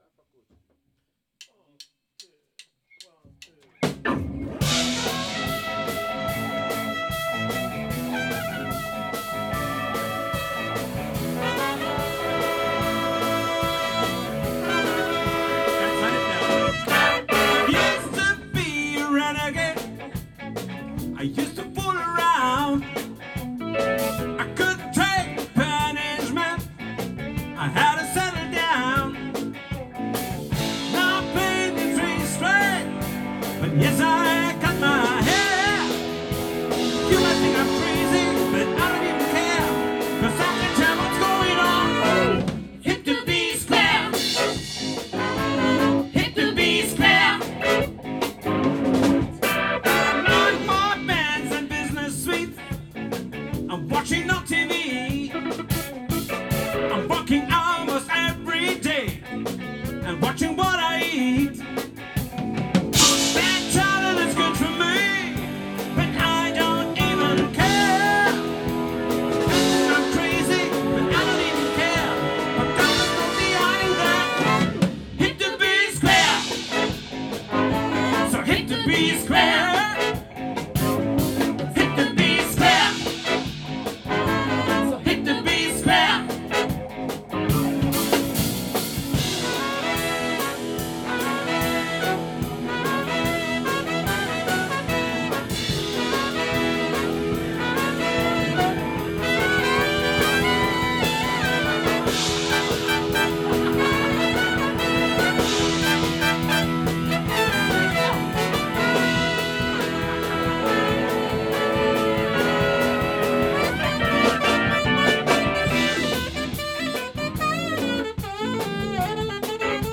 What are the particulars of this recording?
· Kanal-Modus: mono · Kommentar